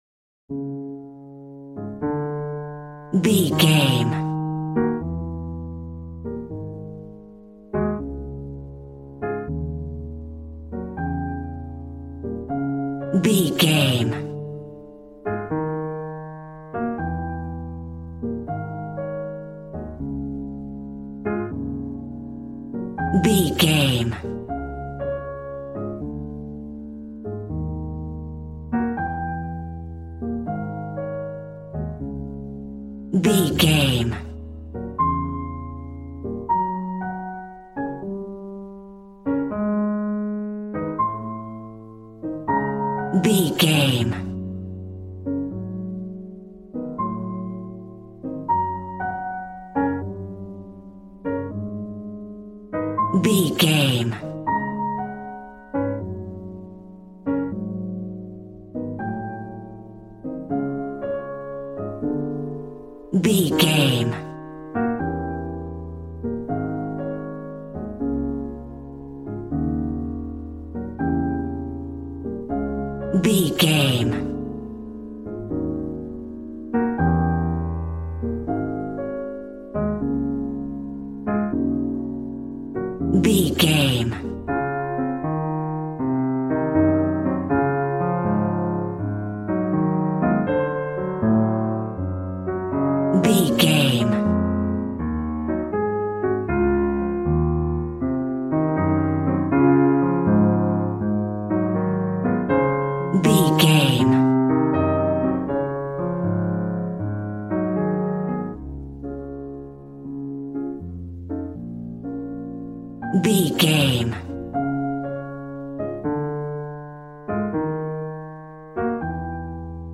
Smooth jazz piano mixed with jazz bass and cool jazz drums.,
Aeolian/Minor
B♭
drums